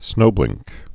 (snōblĭngk)